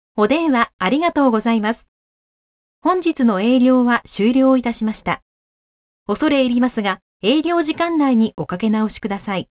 ■アナウンスサービス１